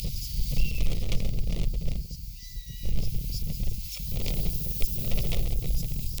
Aguilucho Colorado (Buteogallus meridionalis)
Nombre en inglés: Savanna Hawk
Localidad o área protegida: San Salvador
Condición: Silvestre
Certeza: Observada, Vocalización Grabada
Aguilucho-colorado_1.mp3